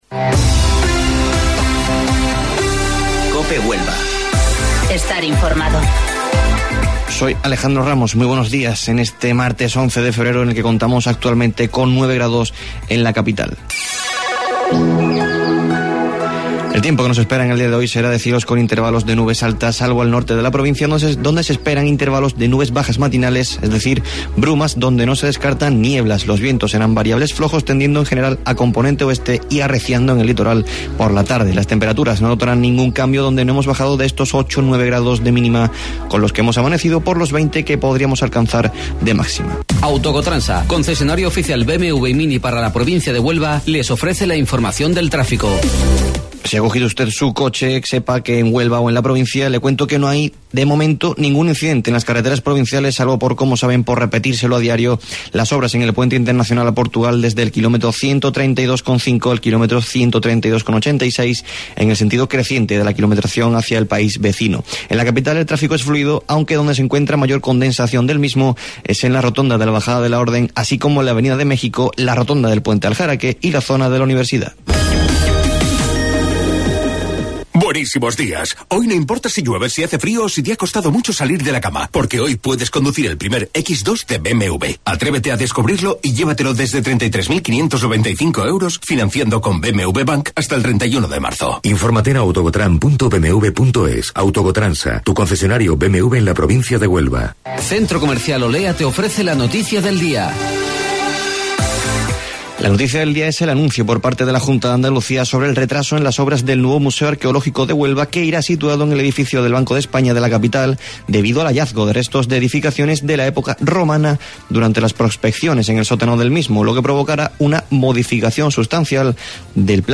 AUDIO: Informativo Local 08:25 del 11 de Febrero